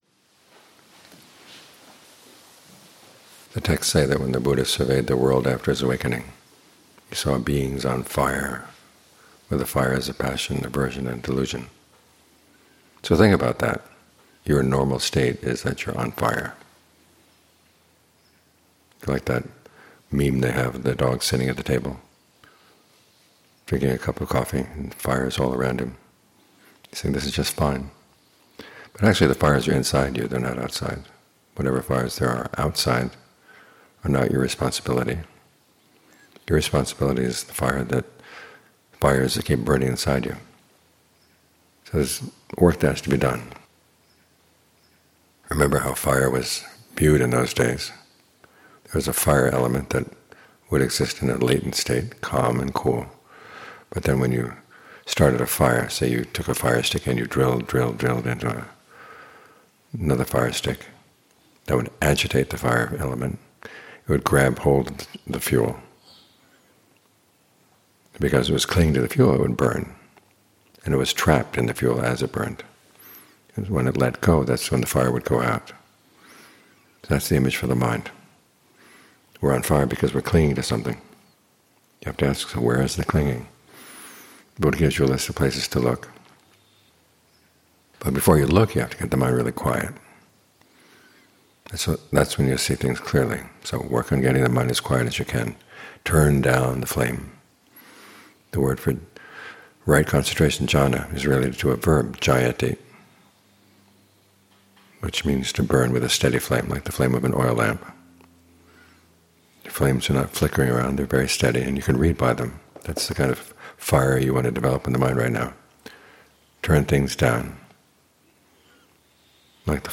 Morning Talks